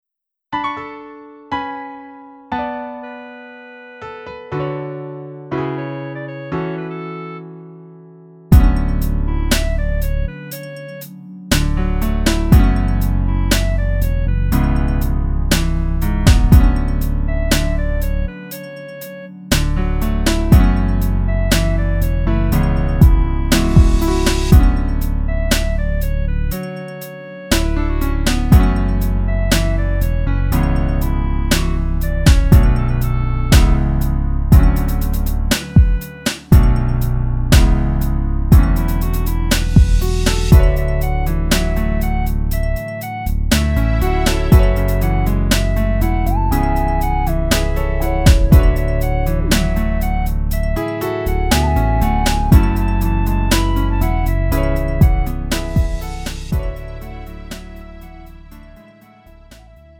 음정 -1키 3:09
장르 구분 Lite MR